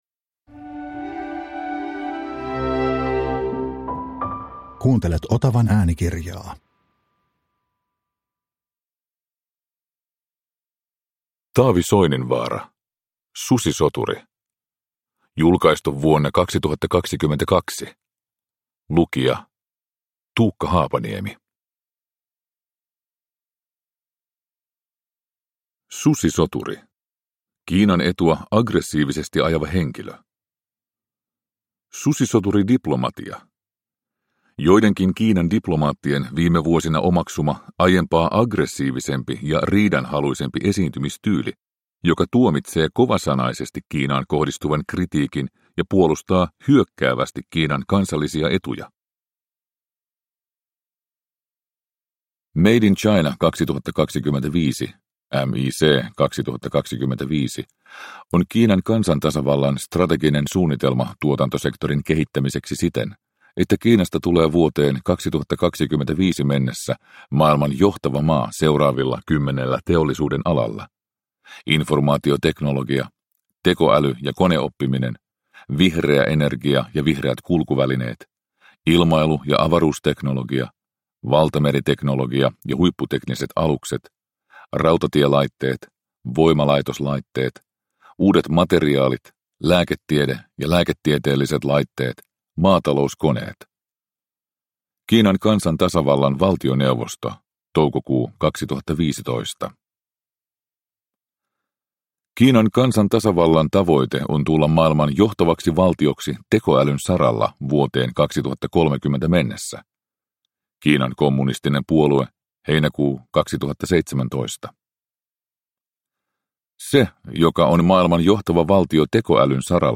Susisoturi – Ljudbok – Laddas ner